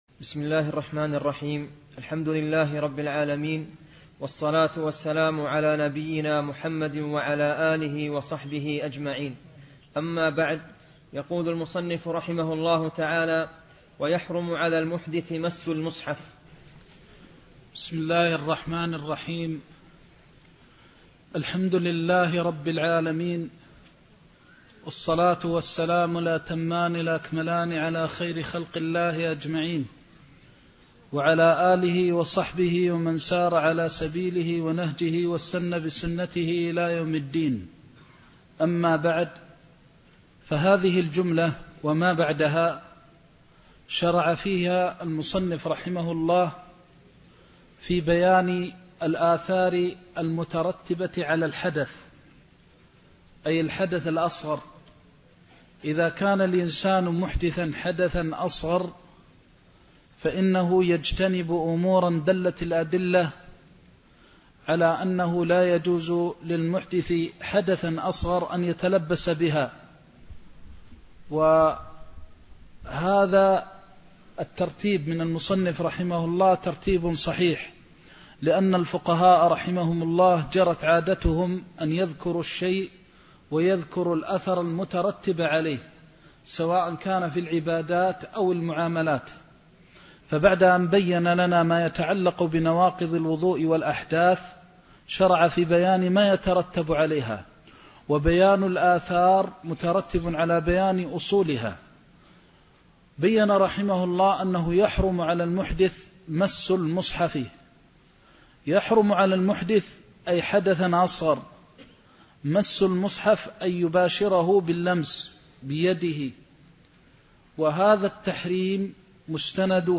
زاد المستقنع كتاب الطهارة (21) درس مكة